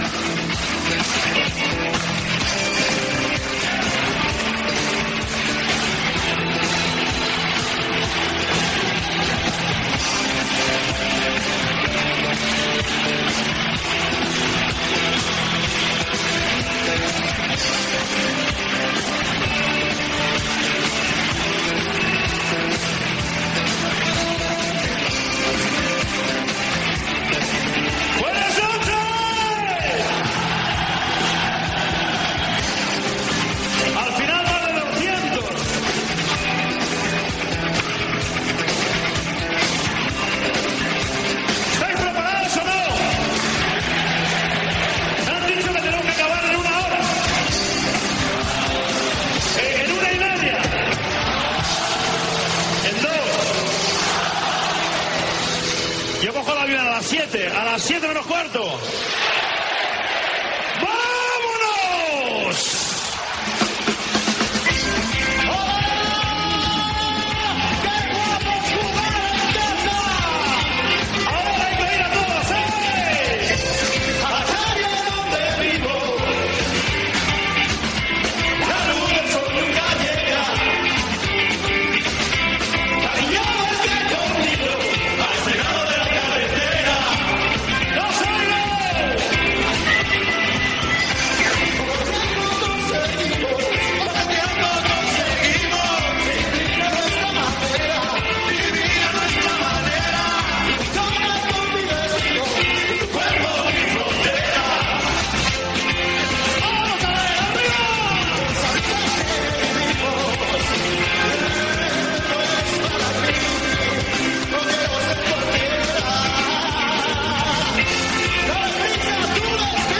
edf6f385eea96f4c8d87d4f1289d58e3c6d16b18.mp3 Títol Cadena COPE Emissora Ràdio Popular de Barcelona (COPE Barcelona) Cadena COPE Titularitat Privada estatal Nom programa El tirachinas Descripció Especial fet des del Palacio de los Deportes de Santander per celebrar quatre anys de la secció "El radiador" feta pel Grupo Risa.
Gènere radiofònic Esportiu